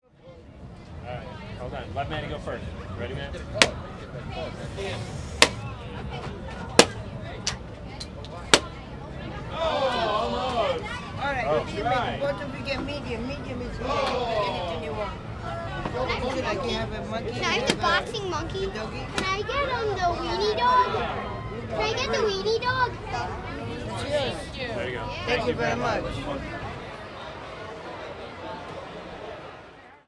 Balloon Game at Arlington Heights Carnival
Category 🗣 Voices
balloon childrens-voices crowd field-recording groans pop sound effect free sound royalty free Voices